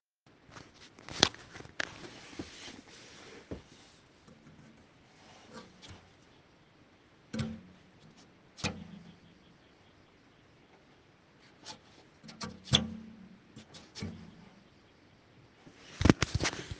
Lüfter machen komische Geräusche
So wie ich sehe, kommen die Geräusche von oben. Also aus meiner AIO (MSI MAG CoreLiquid 360R).